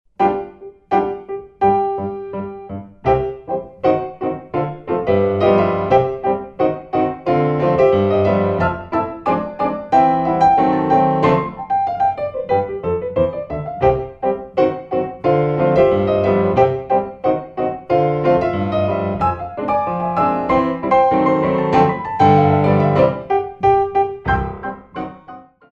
Battements Frappé